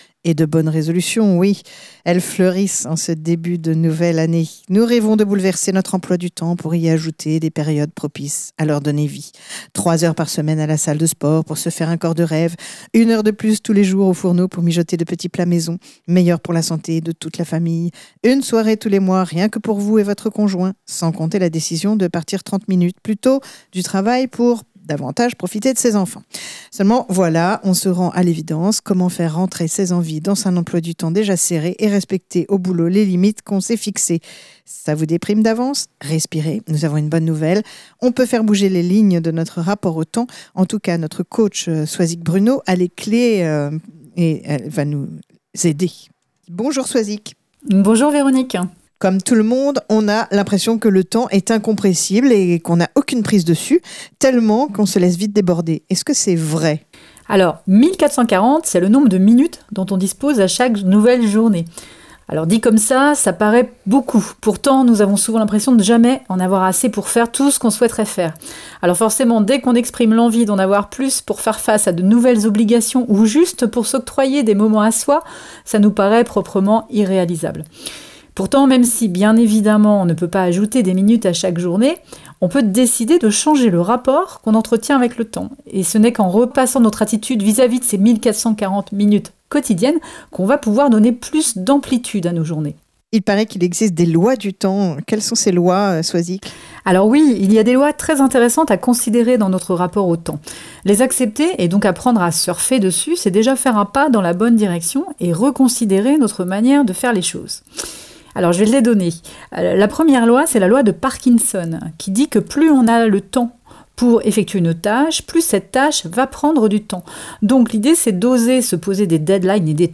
par Chronique Radio